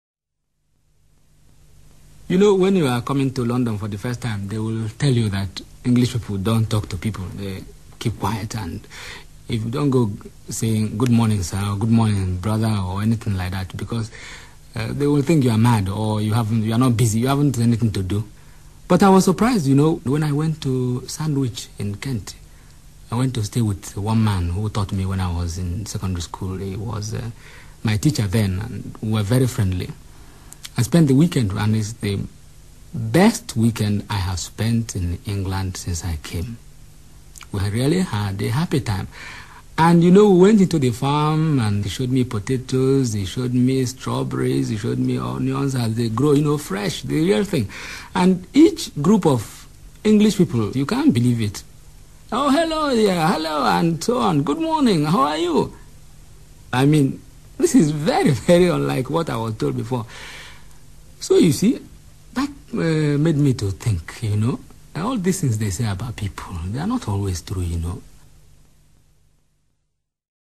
A Nigerian Accent
A native of Nigeria
El inglés de los países de esta zona del continente africano posee un ritmo marcado por el tiempo de duración de una sílaba y no por la posición del acento, como ocurre con el inglés de la India y de Hong Kong.
Por ejemplo, el sonido /ə/ no se utiliza tan a menudo, y palabras breves como of, to o and tienden a pronunciarse en su forma fuerte.
También se pueden apreciar algunas diferencias con respecto a los sonidos, particularmente en los vocálicos, a menudo debido a la influencia de las lenguas locales del oeste de África, que utilizan un número más reducido de ellos.
NIGERIA-Lagos.mp3